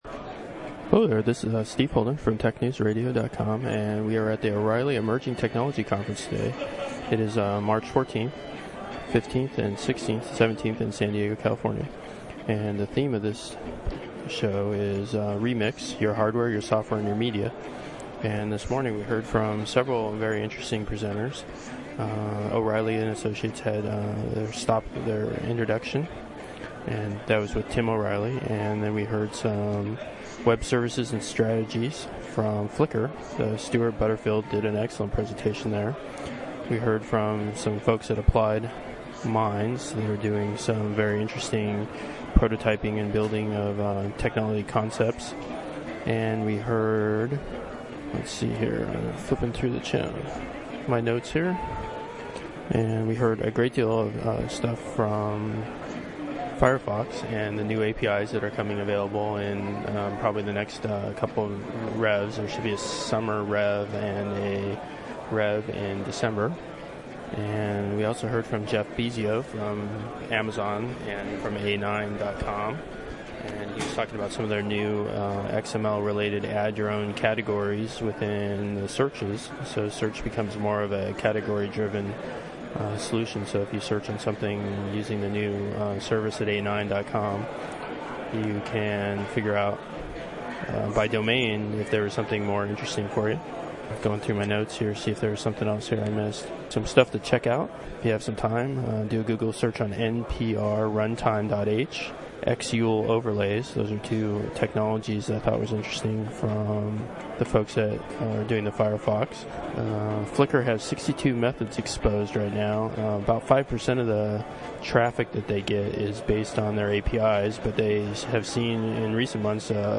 This is an update from the Emerging Technology Conference (etech) with the theme of "Remix."
NOTE: Recorded using my new mobile system.